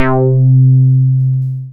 RESO BASS.wav